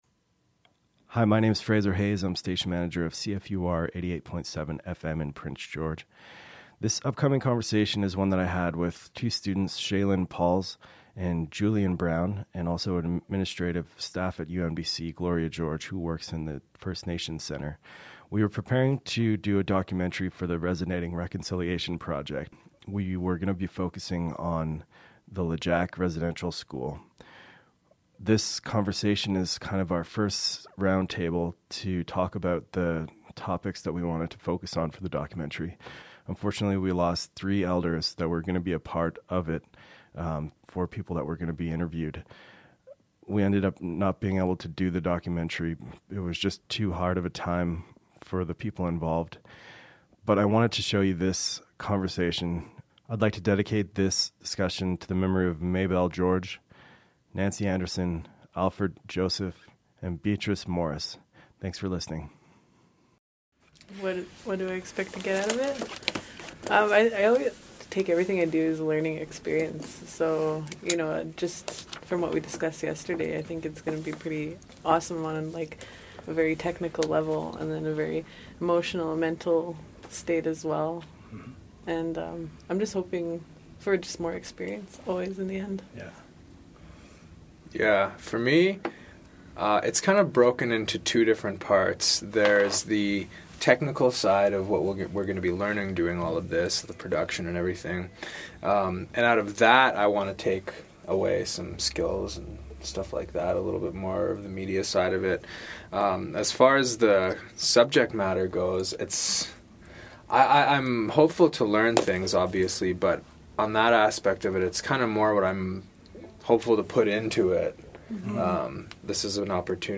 Producers initial roundtable discussion for the Resonating Reconciliation project.